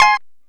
Lng Gtr Chik Min 13-F3.wav